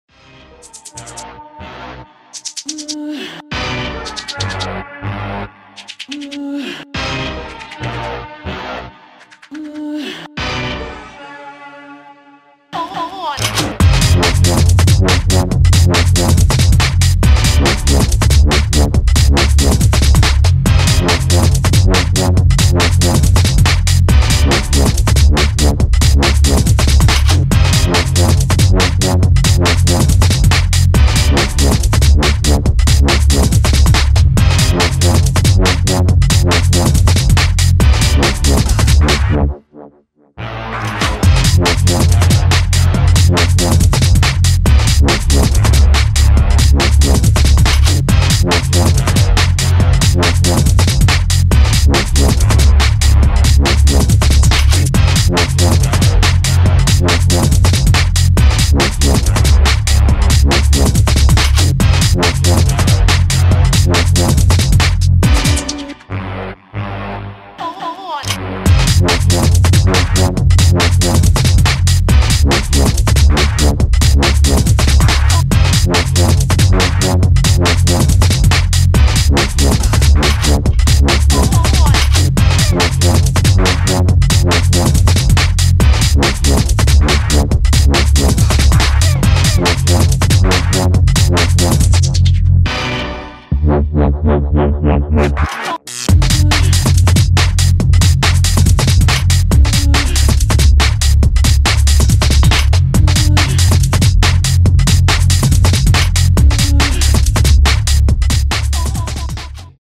BASS / BREAKS /DUB STEP / GRIME